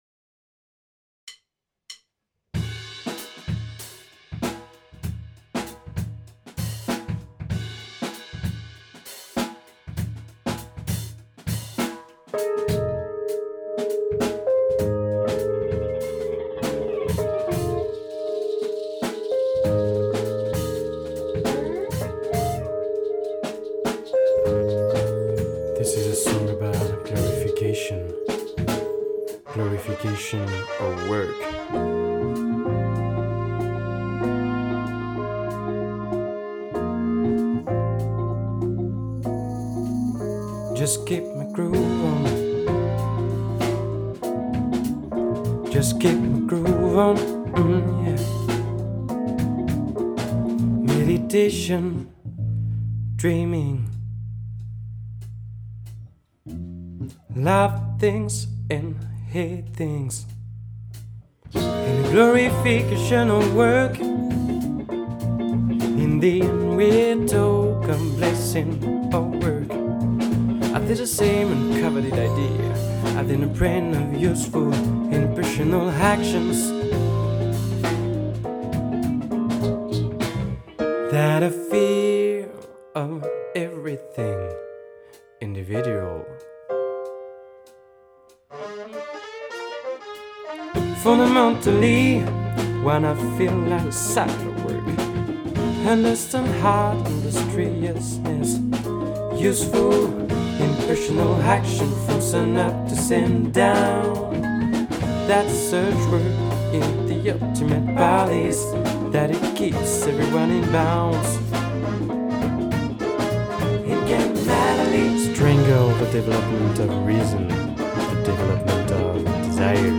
une compo perso dans un style funk-jazz